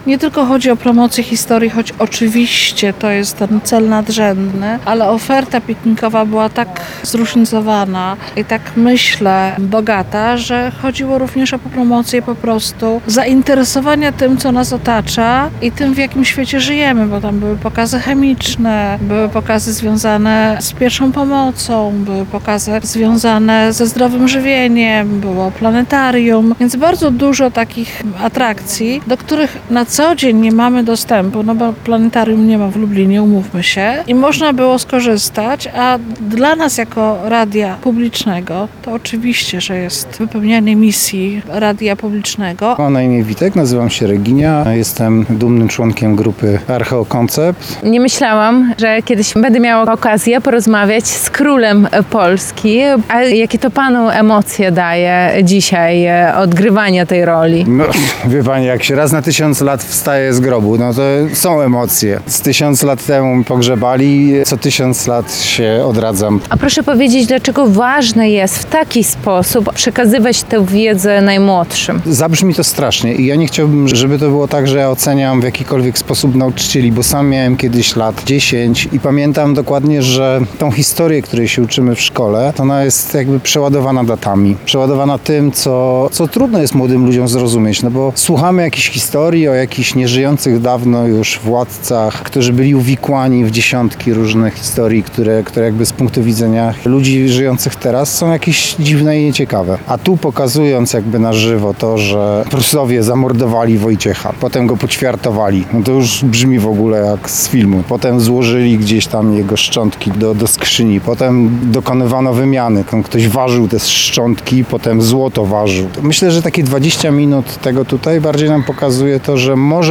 Spektakl rock–operowy, rekonstrukcje historyczne, a także podróż w czasie za pomocą okularów VR. W Radiu Lublin odbył się piknik edukacyjny z okazji Tysiąclecia Korony Polskiej.